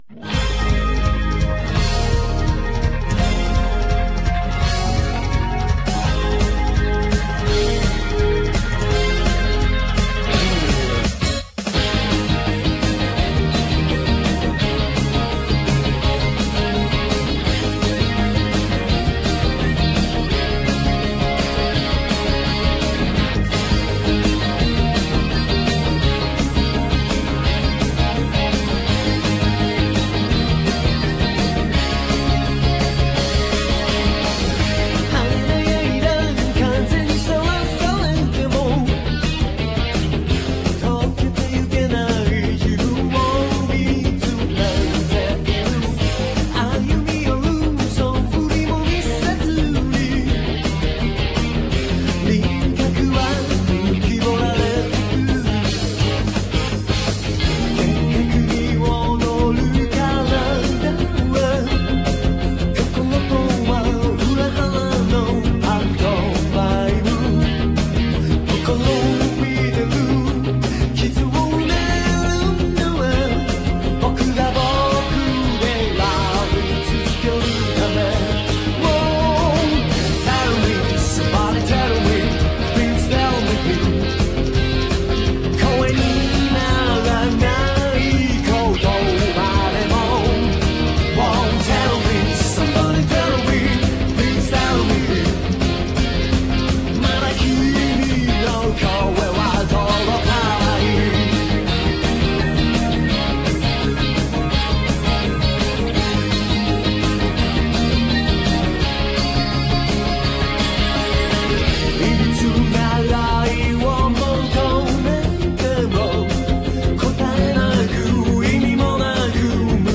a pretty melody, kind of sad.